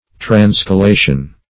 Search Result for " transcolation" : The Collaborative International Dictionary of English v.0.48: Transcolation \Trans`co*la"tion\, n. Act of transcolating, or state of being transcolated.